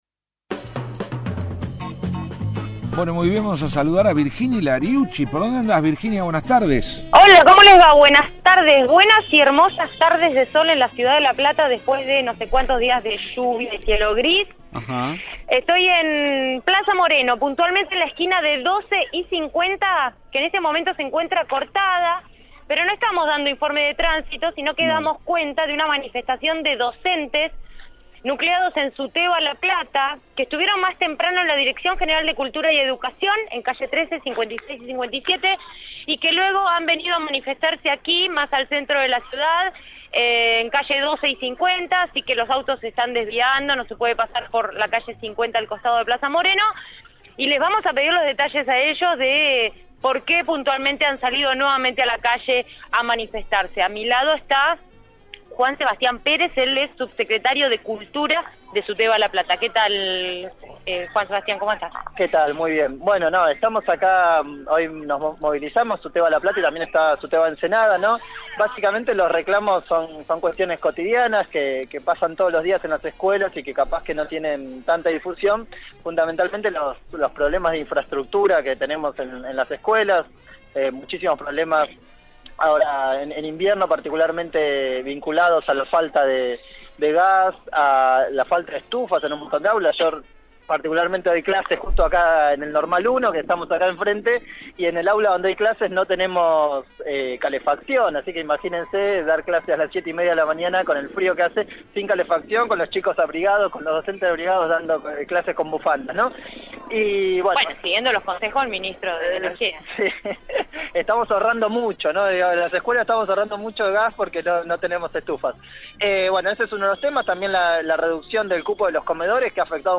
Móvil/ Reclamo de Suteba La Plata y Ensenada por la situación educativa